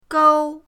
gou1.mp3